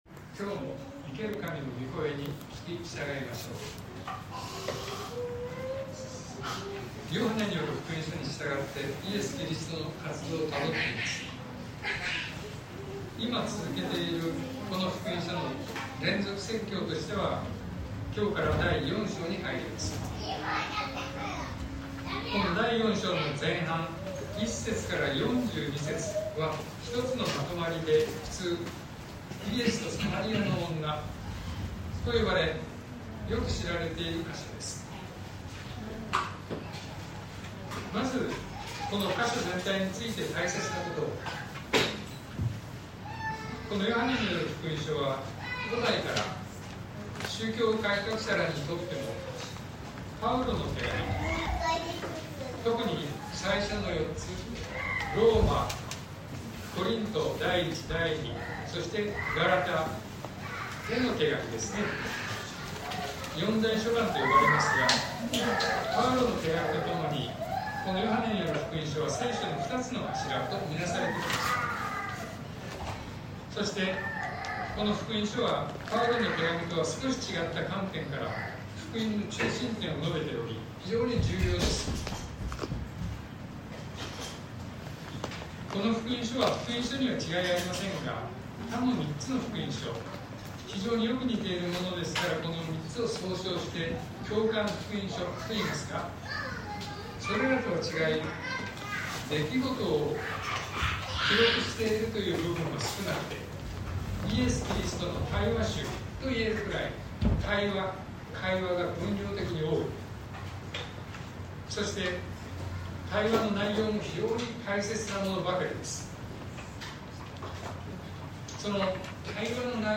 東京教会。説教アーカイブ。